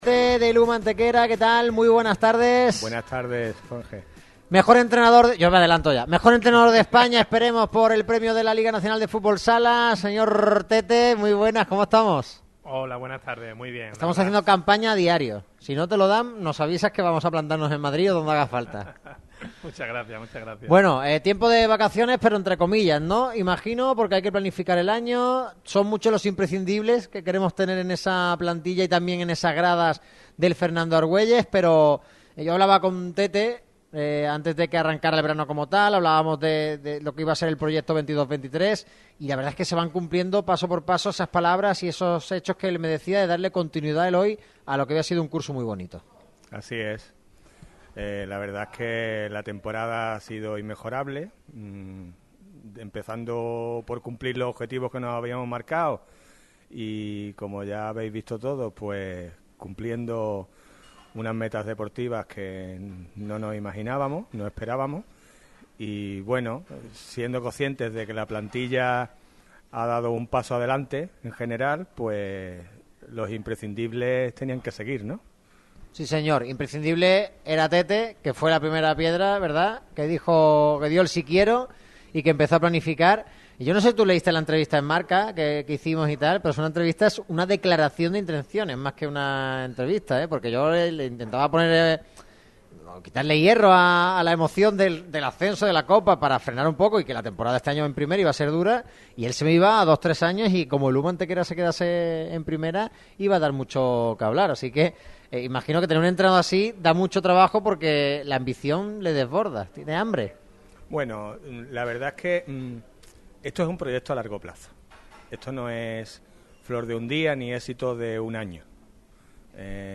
Radio MARCA Málaga se desplaza hasta el templo blanquiazul de Pizzería Frascati.